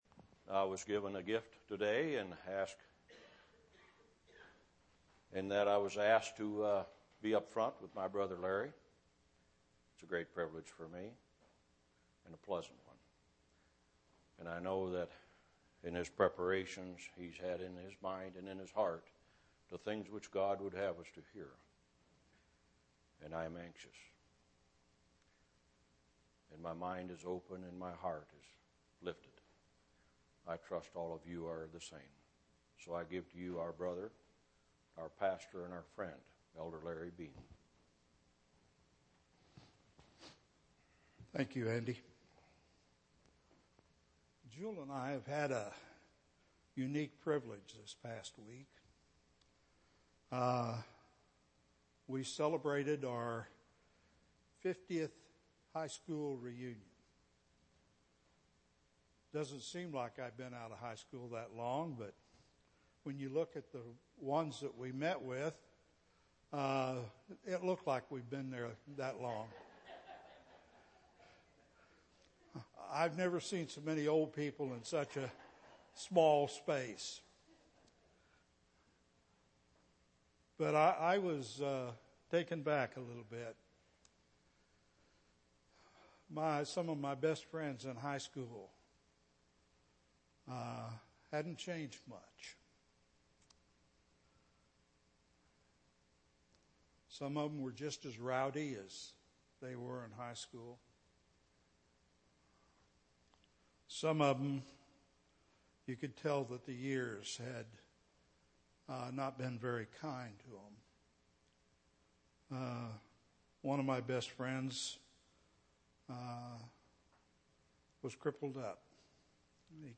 10/14/2012 Location: East Independence Local Event